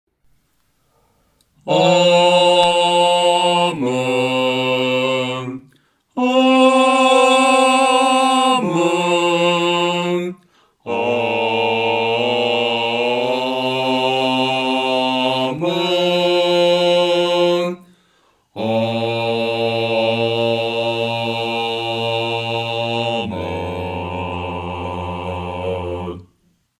男低
本首圣诗由网上圣诗班录制